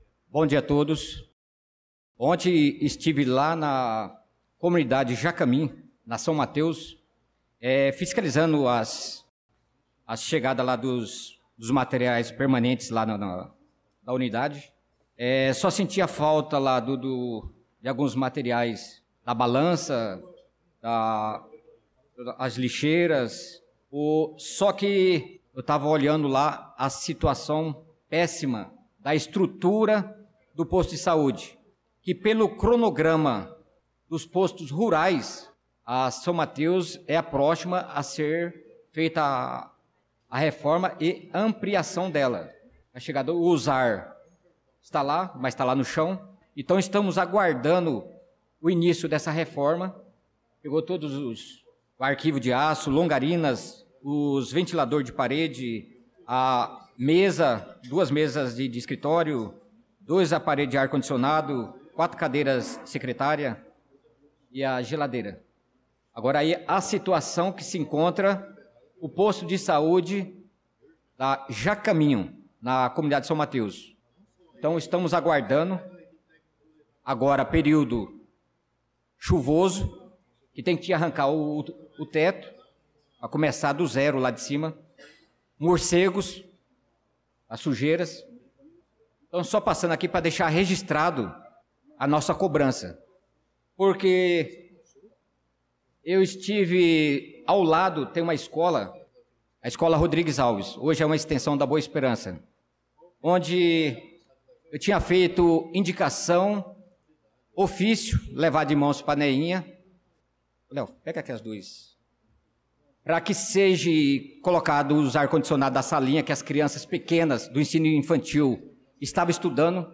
Pronunciamento do vereador Naldo da Pista na Sessão Ordinária do dia 11/03/2025